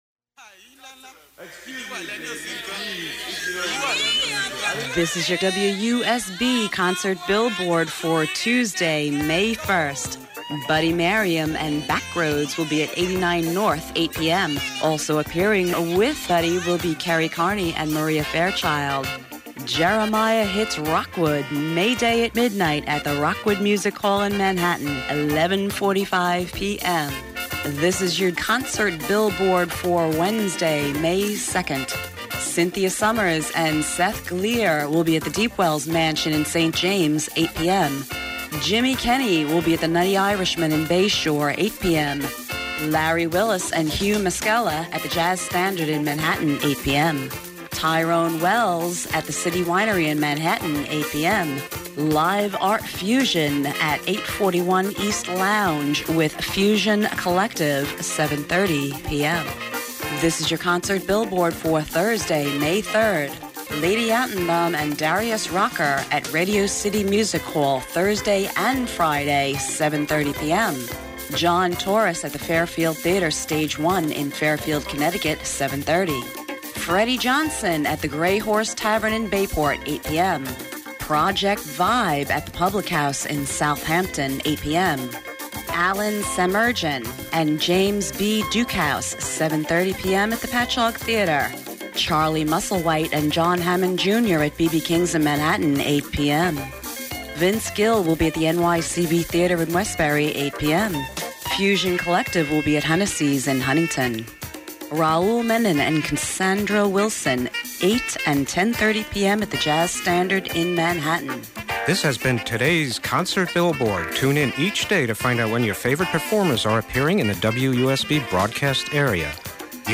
Problems with the microphone